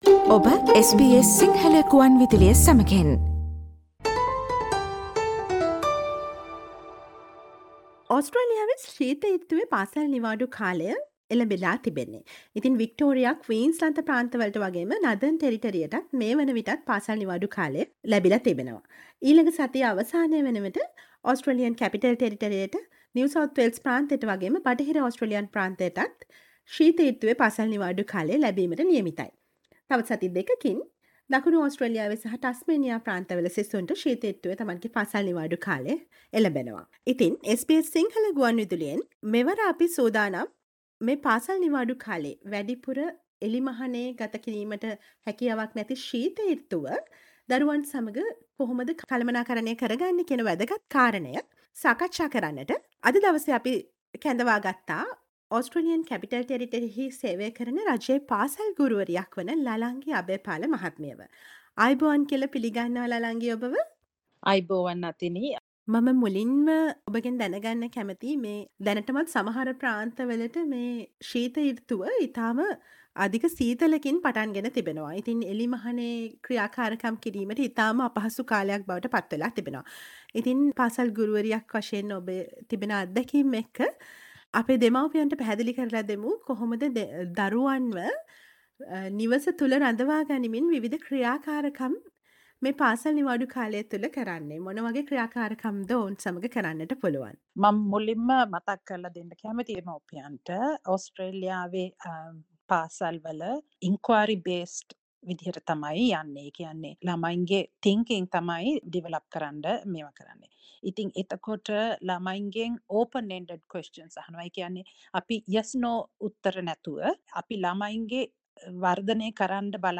SBS සිංහල ගුවන්විදුලි සේවය සිදු කළ සාකච්ඡාව